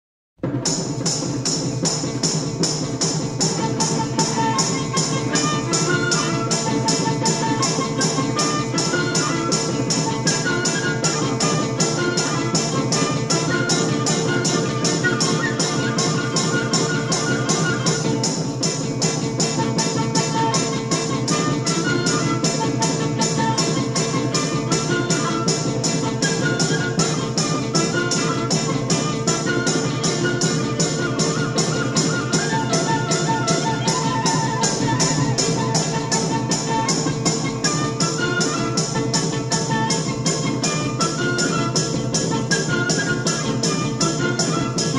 with harmonic-minor twists both sinister and mysterious.